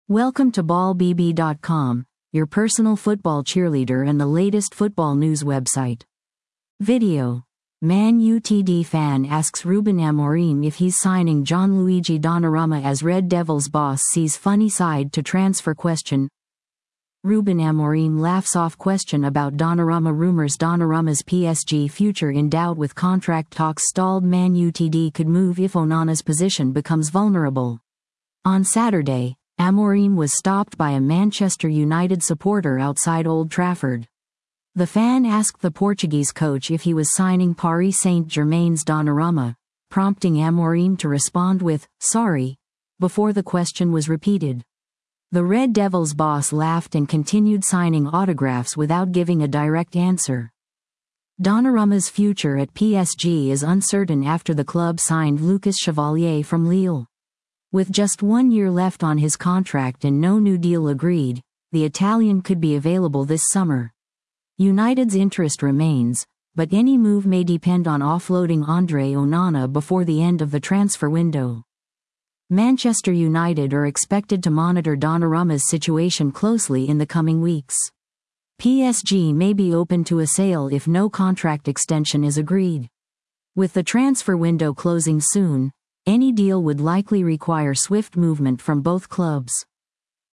• Ruben Amorim laughs off question about Donnarumma rumours
On Saturday, Amorim was stopped by a supporter outside Old Trafford.
The Red Devils boss laughed and continued signing autographs without giving a direct answer.